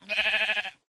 mob / sheep2